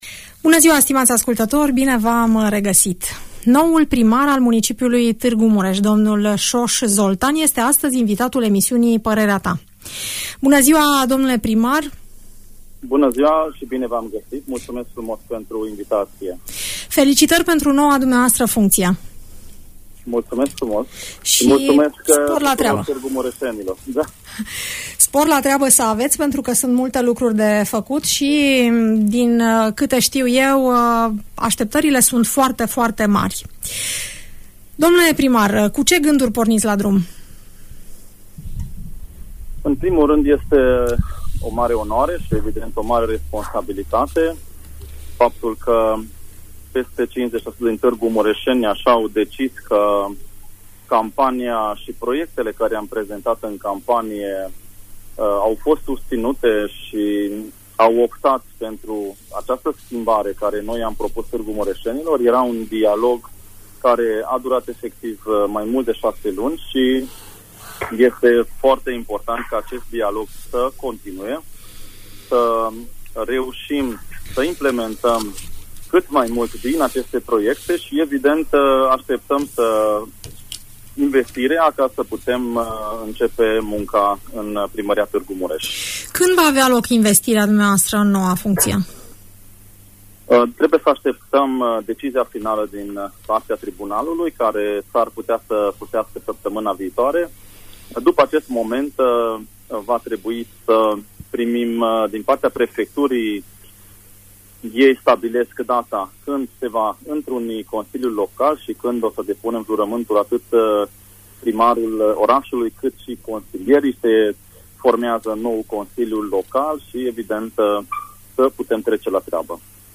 Primarul Soos Zoltan, în direct la Radio Tg. Mureș